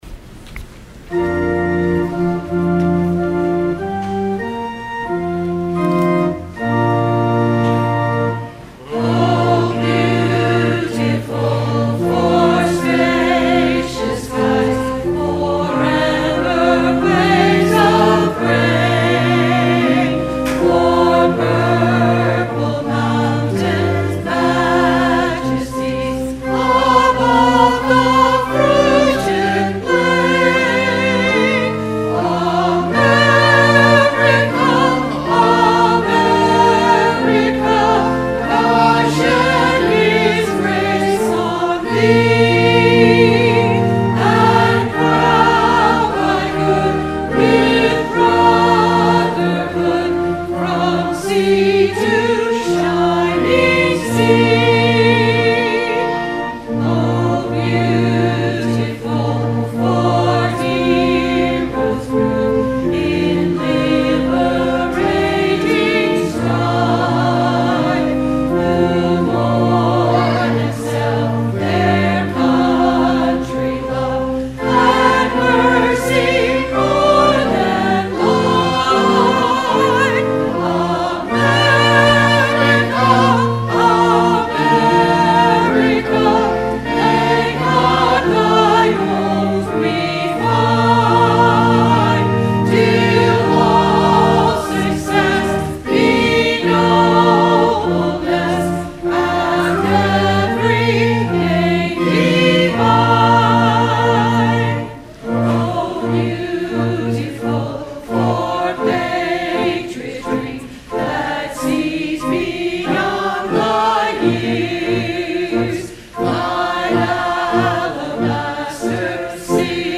Sung by the Church and Choir.
Hymn